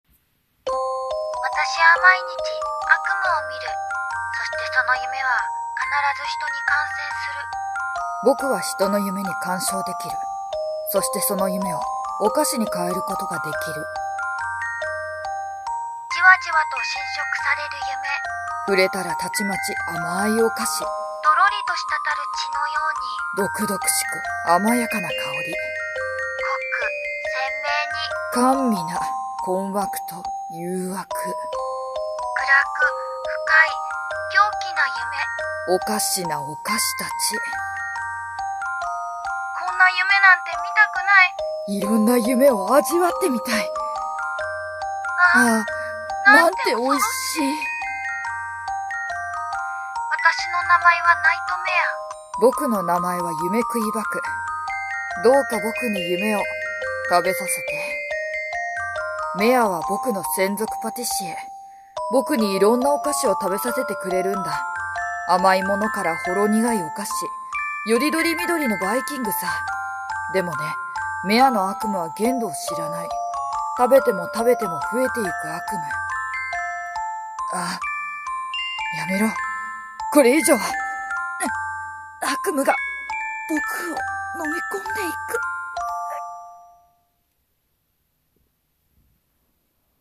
【声劇】夢喰いバクの悪夢なお菓子【掛け合い】